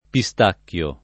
vai all'elenco alfabetico delle voci ingrandisci il carattere 100% rimpicciolisci il carattere stampa invia tramite posta elettronica codividi su Facebook pistacchio [ pi S t # kk L o ] s. m.; pl. ‑chi — sim. i cogn.